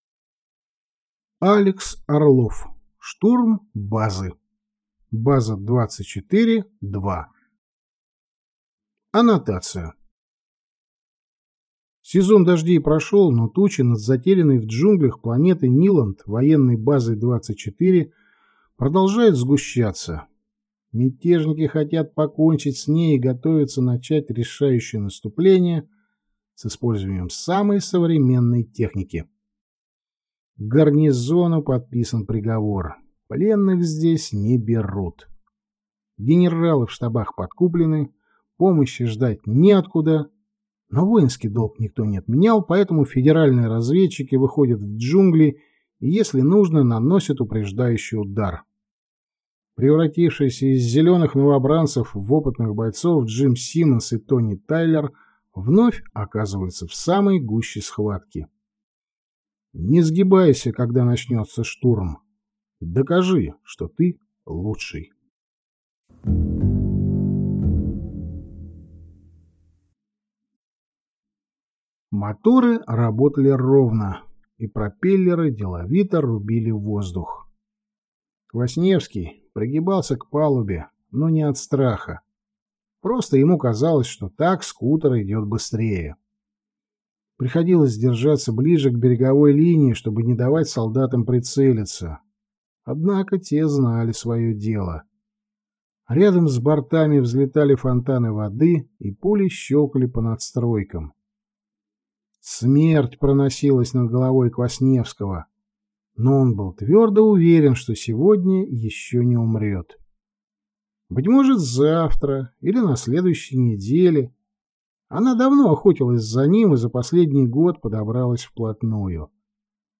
Аудиокнига Штурм базы | Библиотека аудиокниг